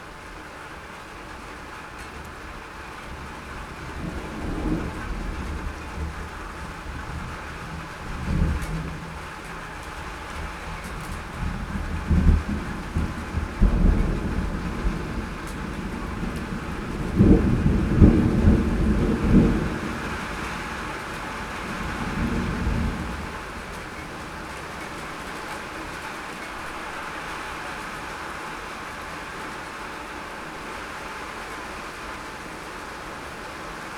• field thunder spring 5.wav
Beautiful rainy spring night, enchanting us by some great thunders and rumbles. Recorded with a TASCAM DR 40.
field_thunder_spring_5_hxy.wav